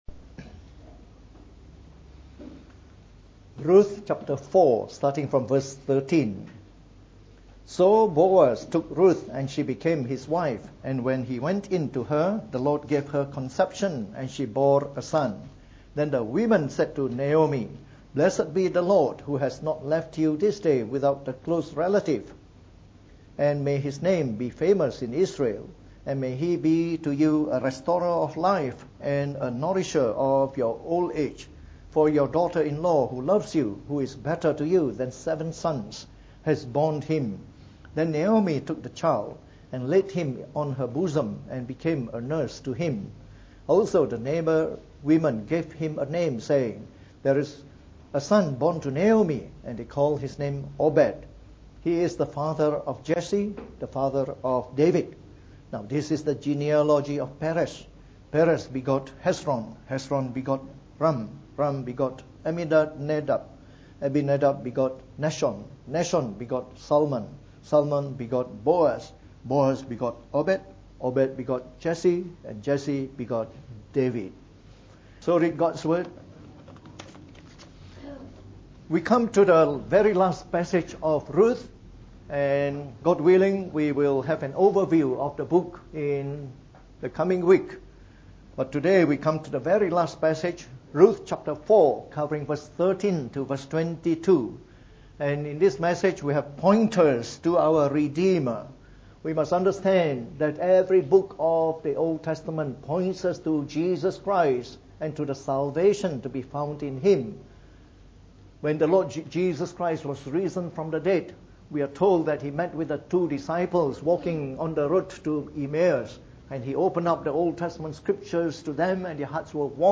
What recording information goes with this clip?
From our series on the Book of Ruth delivered in the Morning Service.